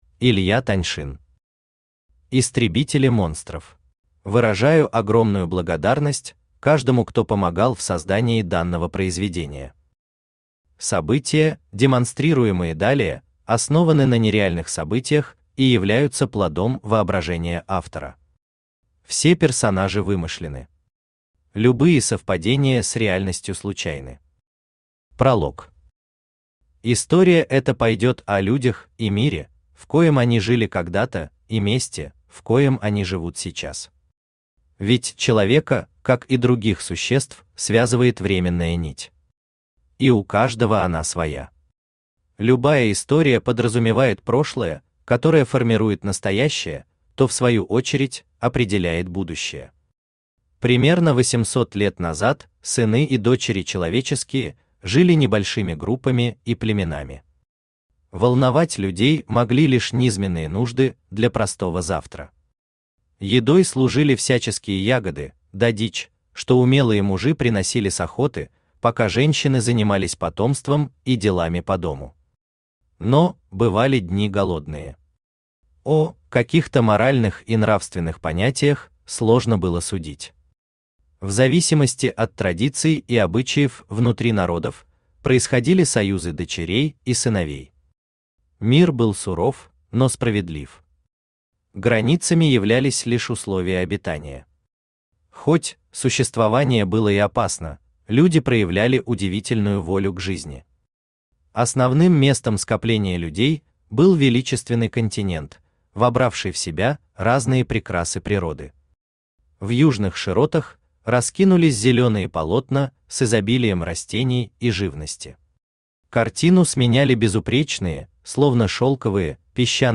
Аудиокнига Истребители Монстров | Библиотека аудиокниг
Aудиокнига Истребители Монстров Автор Илья Викторович Таньшин Читает аудиокнигу Авточтец ЛитРес.